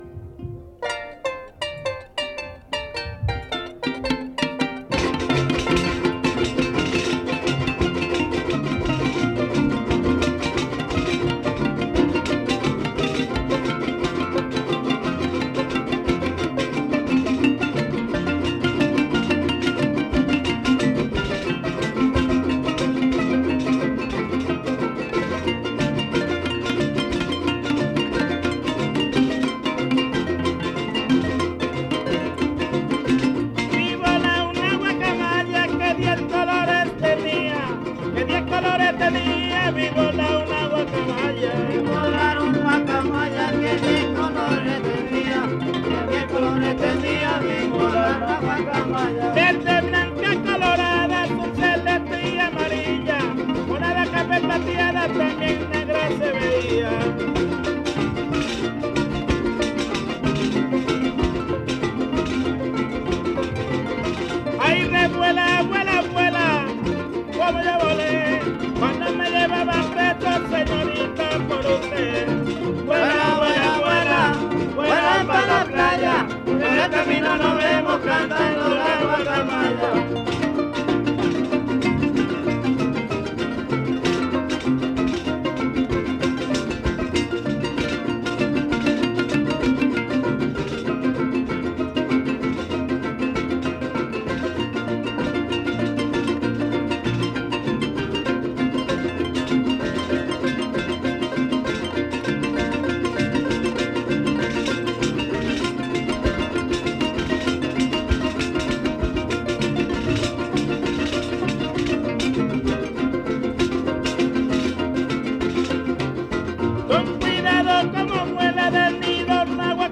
• Papaloapan (Grupo musical)
Noveno Encuentro de jaraneros